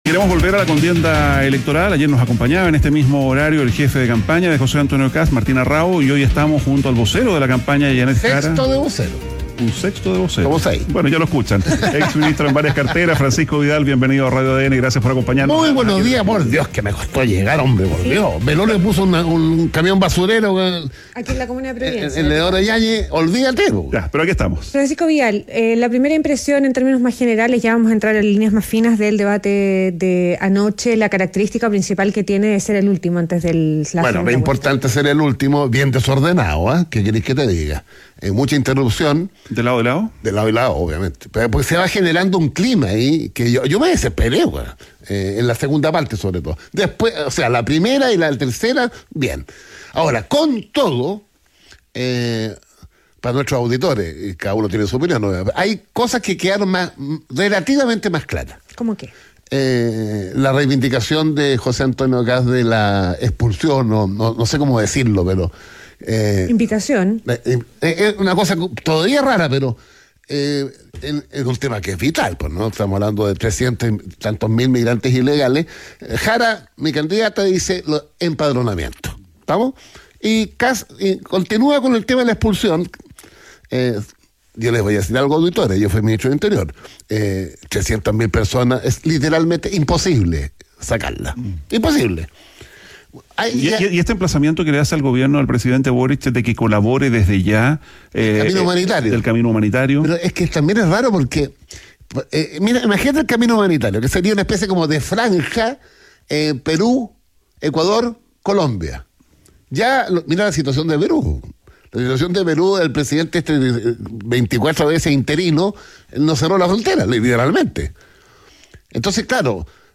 Francisco Vidal conversa con ADN Hoy